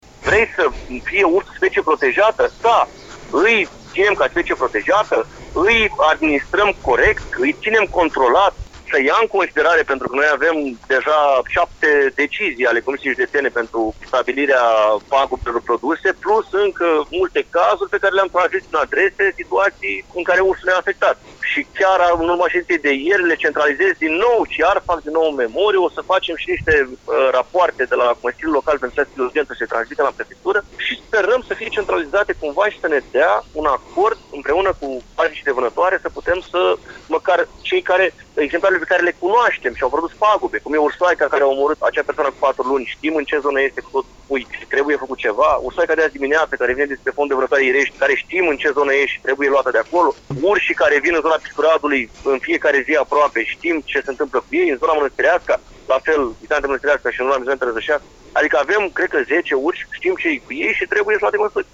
Primarul Dragoș Ciobotaru spune că au fost identificați urșii care în ultima perioadă au provocat pagube majore în gospodării: